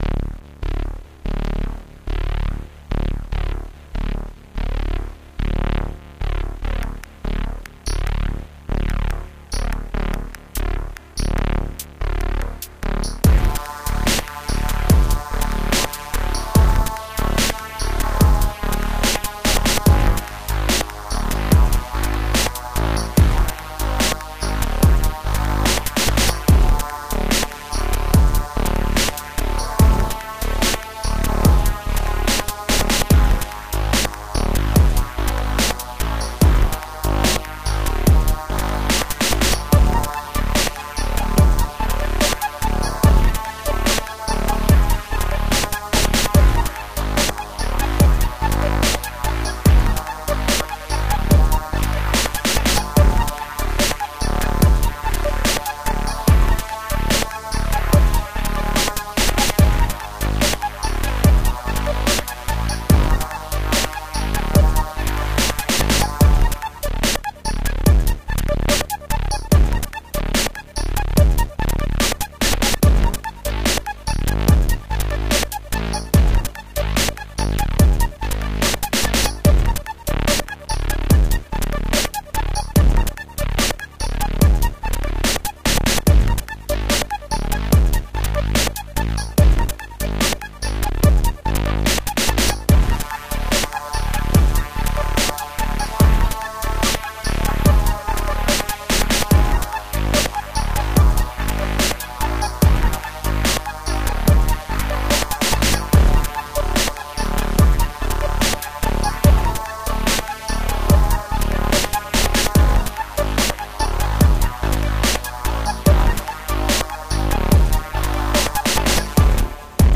Short and simple 8-bit tune